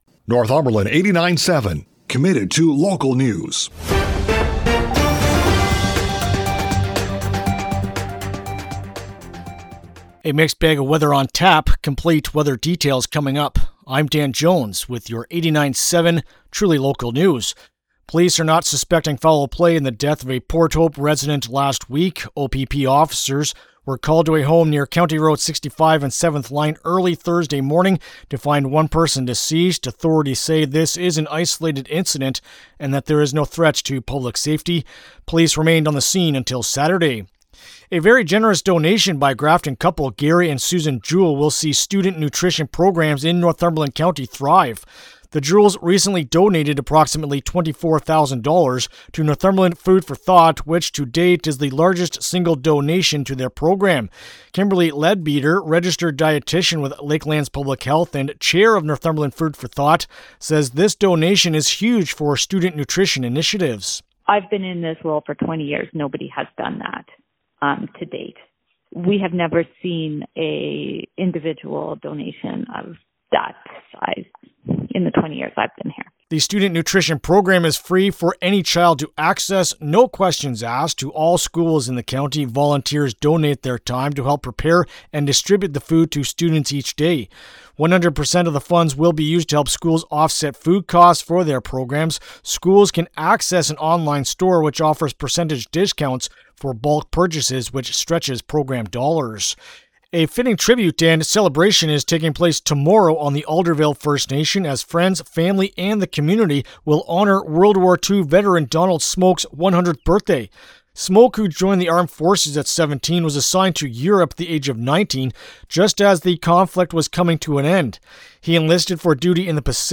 Monday-Feb-23-PM-News-1.mp3